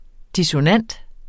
Udtale [ disoˈnanˀd ]